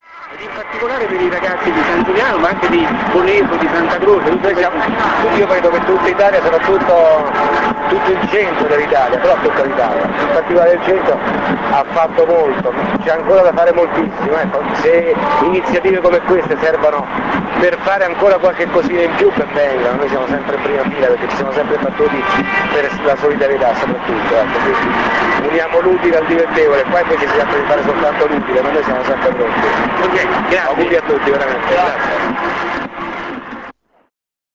Speciale Derby del Cuore - INTERVISTE AI PERSONAGGI